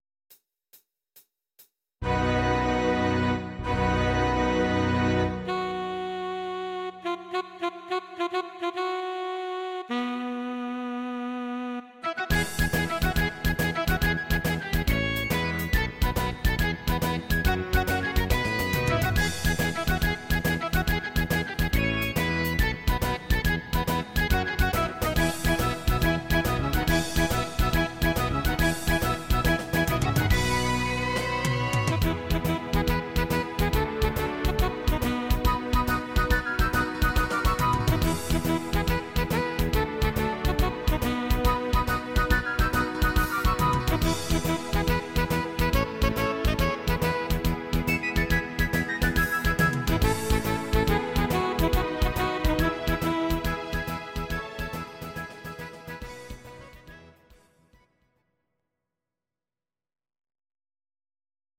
Audio Recordings based on Midi-files
Rock, 1970s